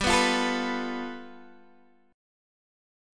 G9 Chord
Listen to G9 strummed